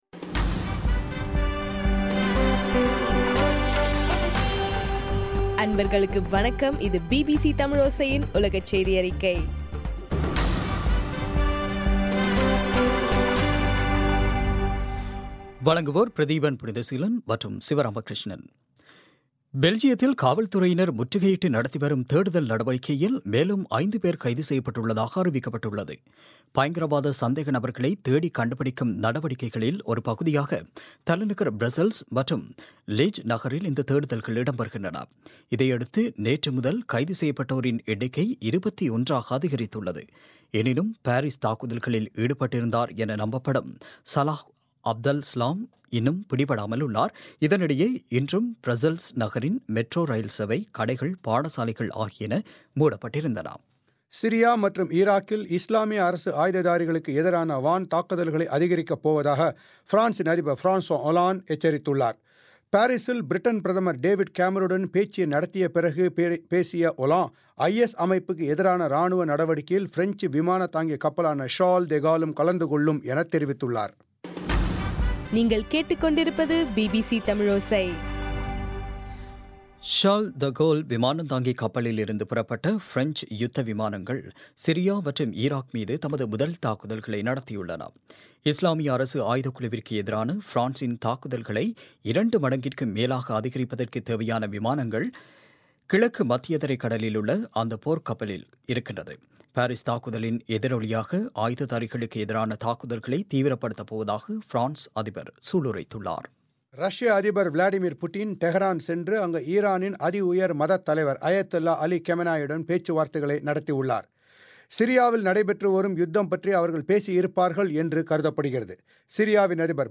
இன்றைய (நவம்பர் 23) பிபிசி தமிழ் செய்தியறிக்கை